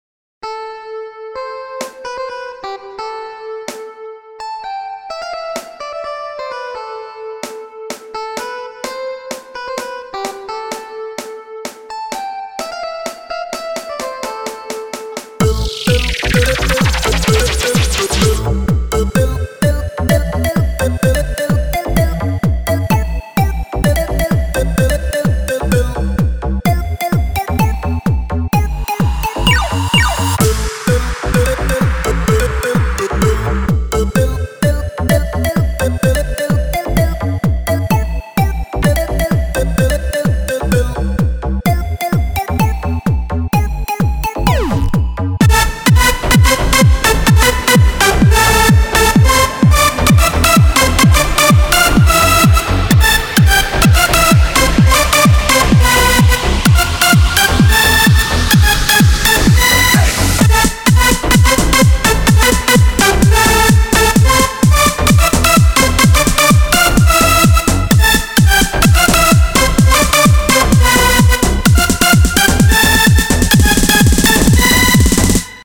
מקצבים שלי
korg 7.MP3 korg 5.MP3 korg 4.MP3 korg 3.MP3 korg 2.MP3 korg 1.MP3 אני בונה די מתחיל ואלו מקצבים שבניתי ואשמח להארות והערות עליהם